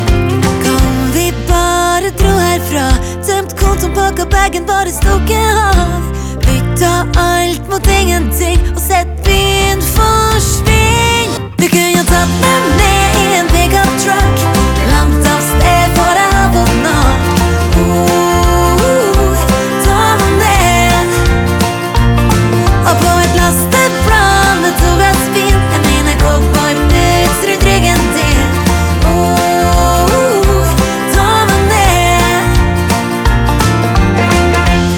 Жанр: Поп музыка / Кантри